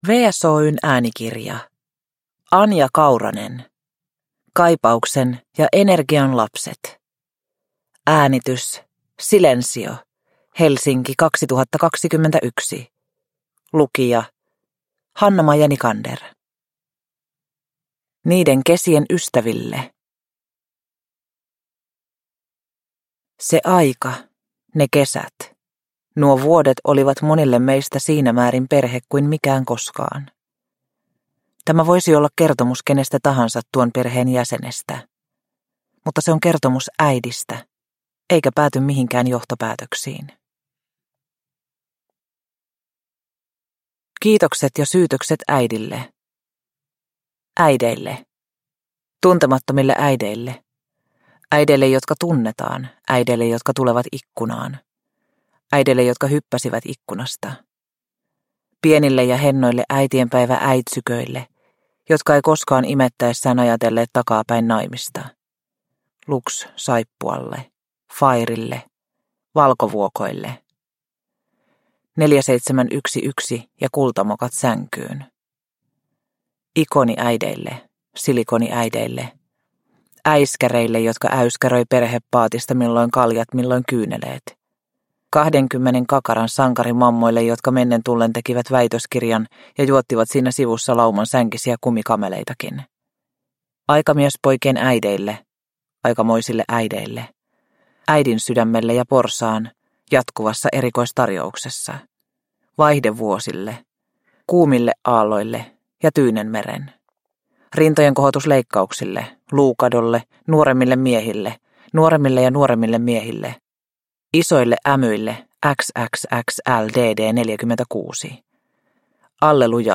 Kaipauksen ja energian lapset – Ljudbok – Laddas ner